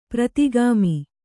♪ pratigāmi